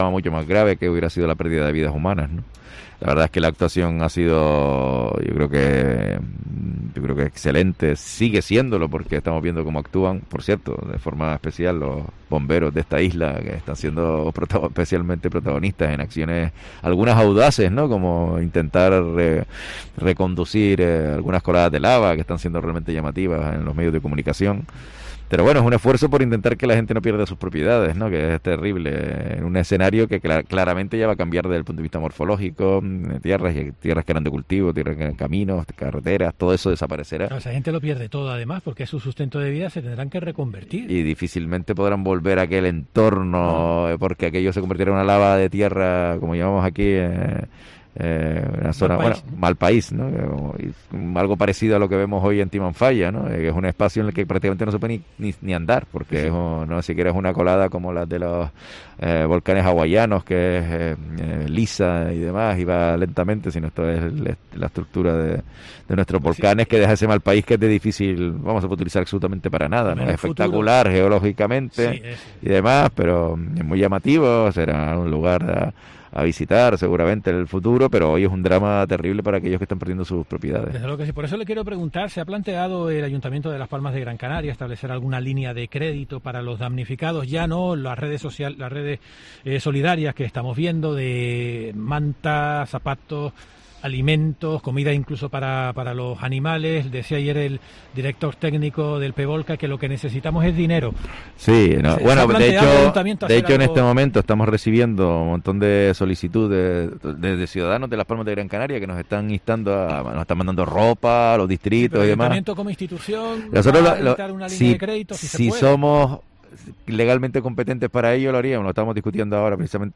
Augusto Hidalgo, alcalde de Las Palmas de Gran Canaria
entrevista